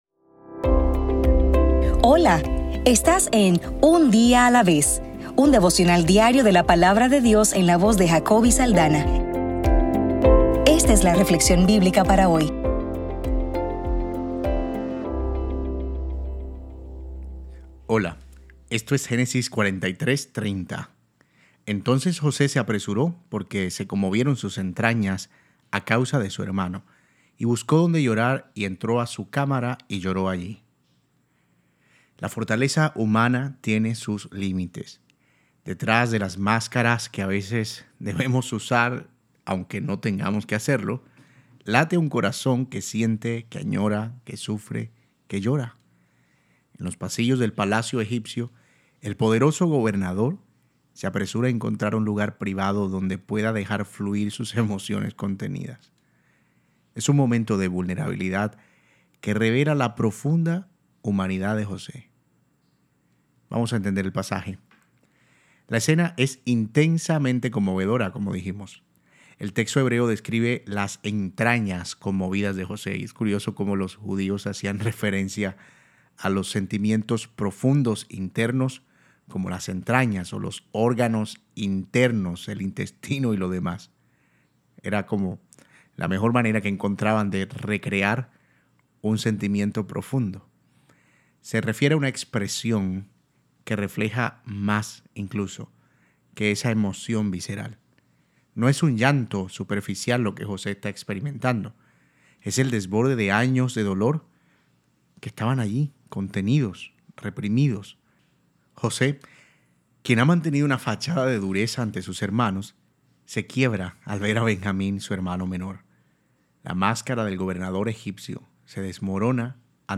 Devocional para el 10 de febrero